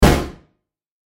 hard_drop_001.ogg